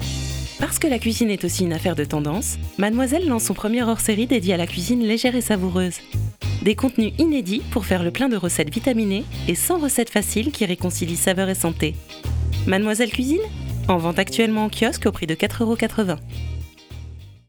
Enregistrements qualité studio
Voix Radio
Direct et accrocheur : le ton idéal pour vos spots publicitaires.
voix-enjouee-souriante.wav